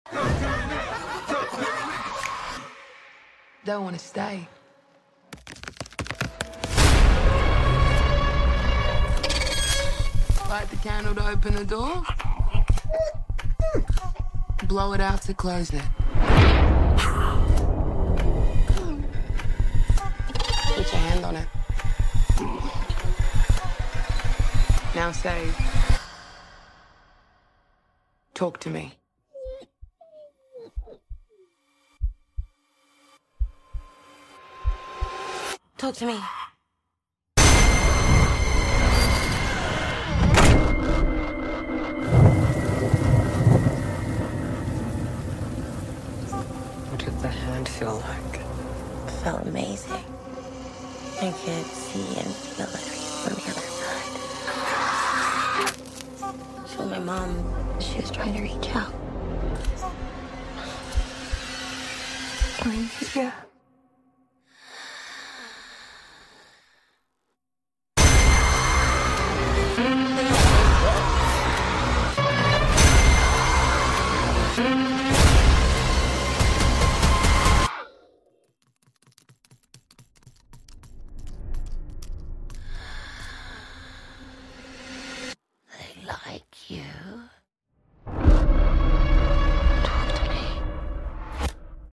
This is my first attempt at a horror/thriller type trailer. Hope it scares you!